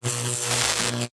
emp-electric-1.ogg